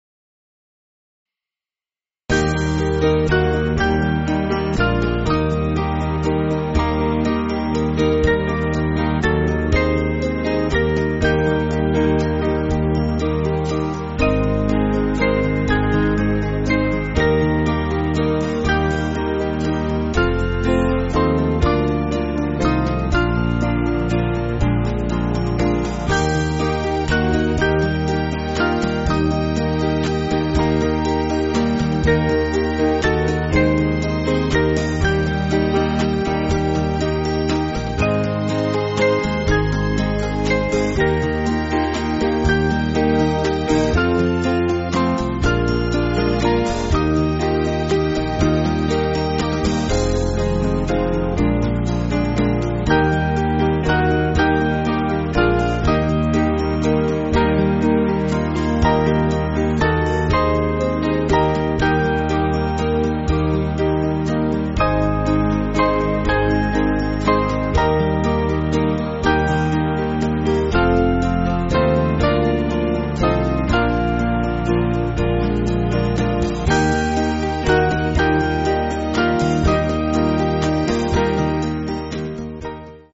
Small Band
(CM)   2/Eb